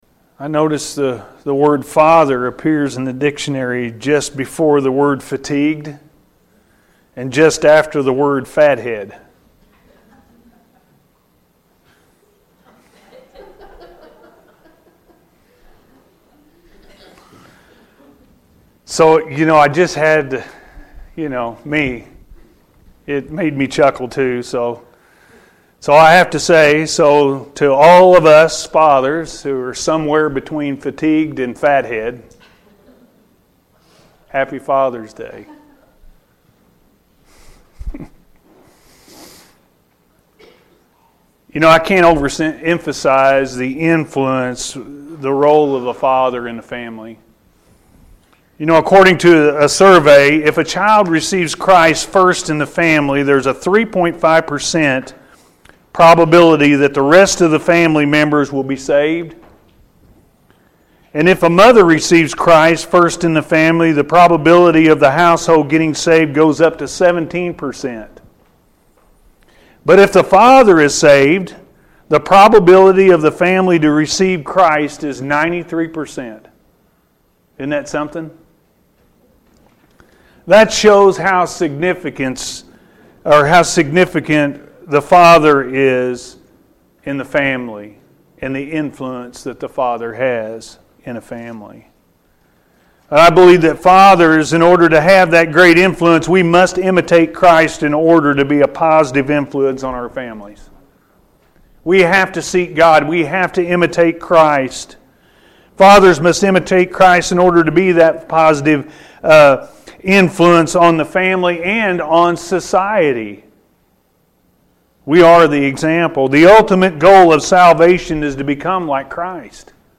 The Role of a Father-A.M. Service